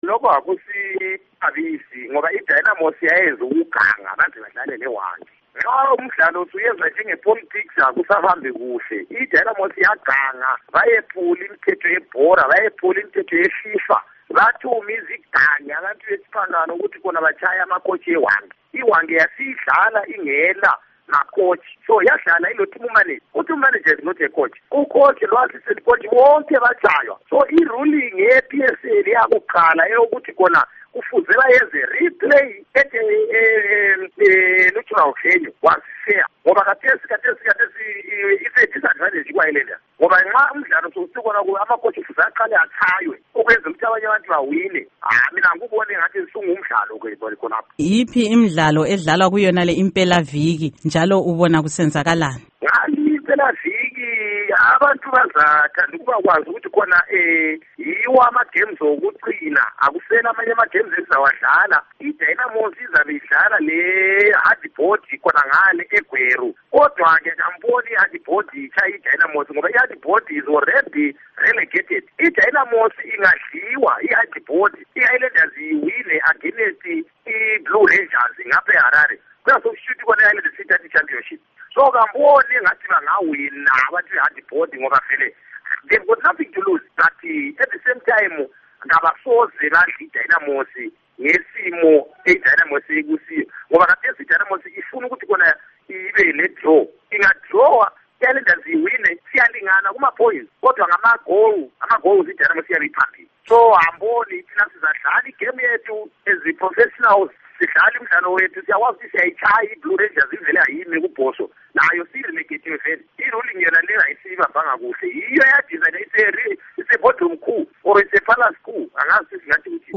Ingxoxo LoKhansila Peter Moyo